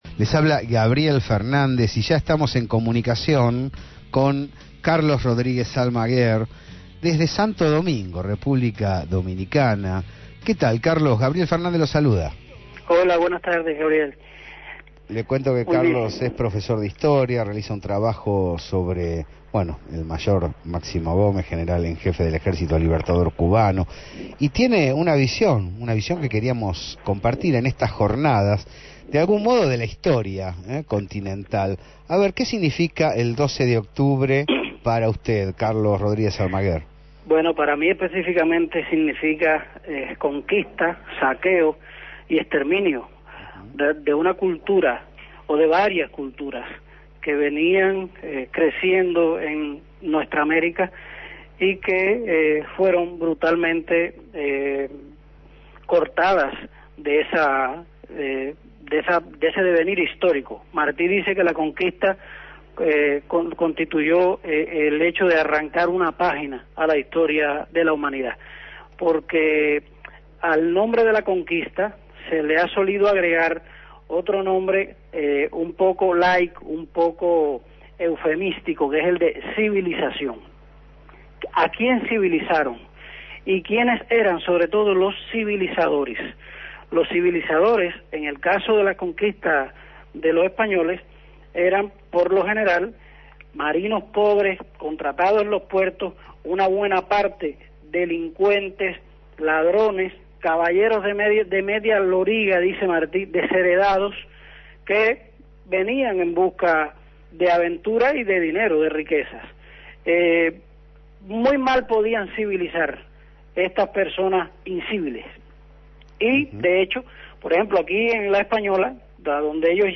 En diálogo con La señal